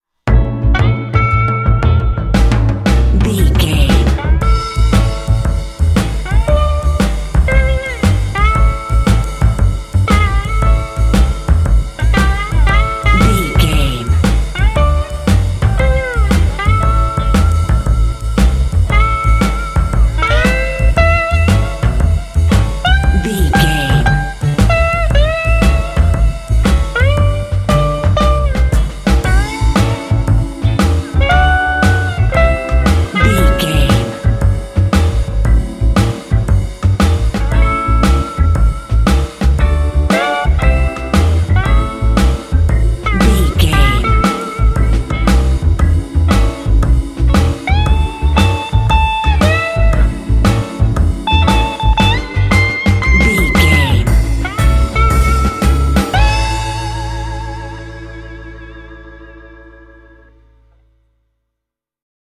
This 12-bar blues track
Uplifting
Aeolian/Minor
groovy
melancholy
bouncy
electric guitar
drums
double bass
electric organ
blues